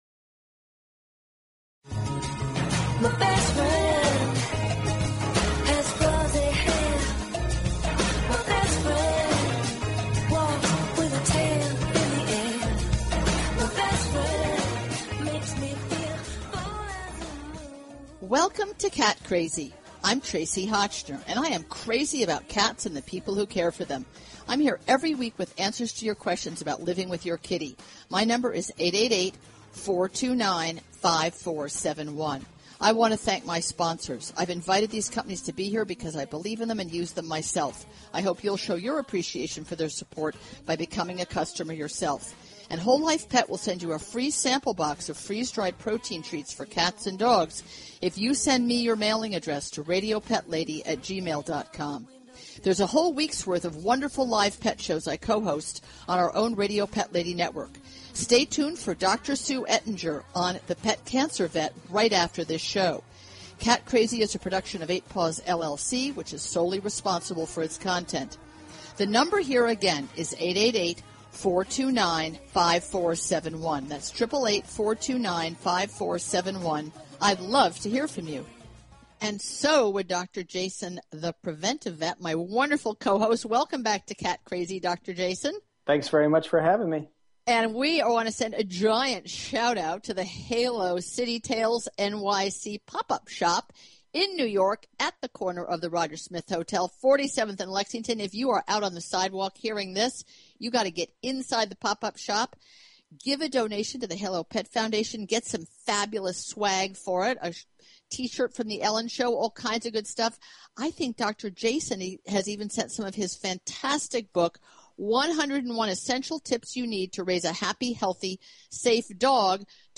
Talk Show Episode, Audio Podcast, Cat_Crazy and Courtesy of BBS Radio on , show guests , about , categorized as